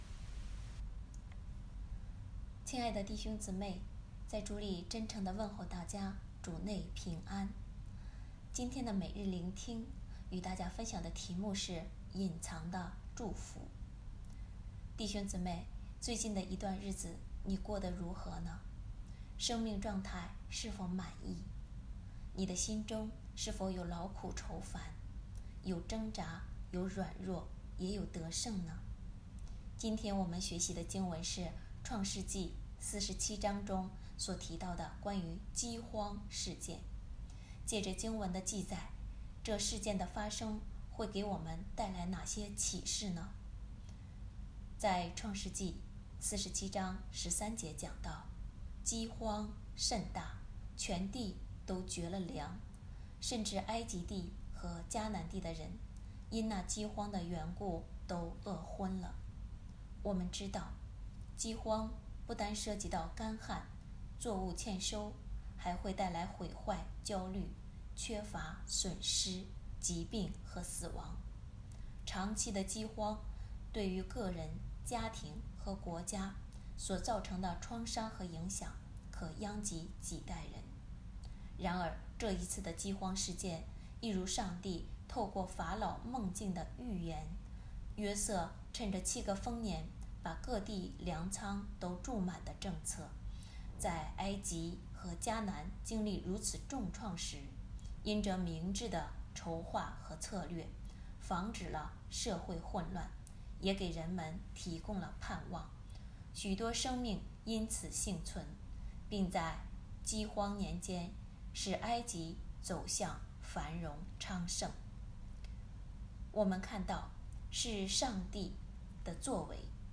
隐藏的祝福 | 北京基督教会海淀堂